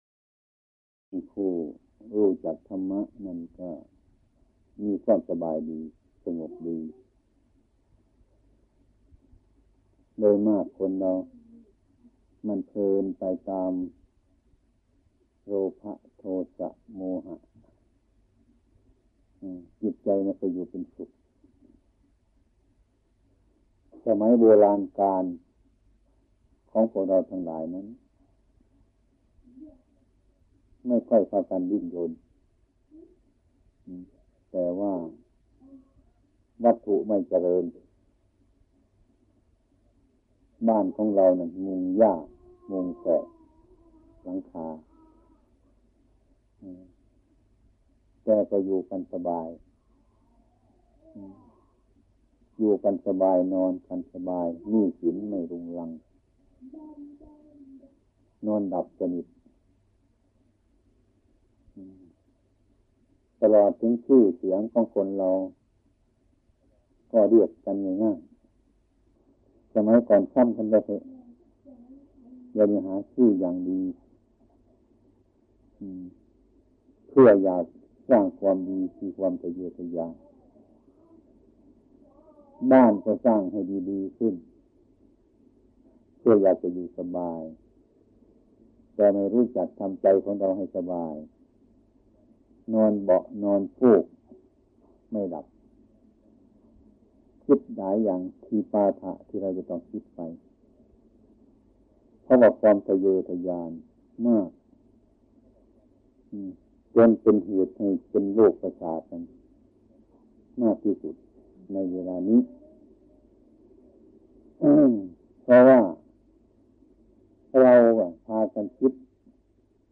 | in พุทธศาสนา คาถาเศรษฐี วัดหนองป่าพง วันคืนล่วงไป ทำอะไรกันอยู่ เอกสาร หลวงปู่ชา สุภทฺโท ธรรมเทศนา 61:03 minutes (55.89 MB) » Download audio file 167 downloads 28 plays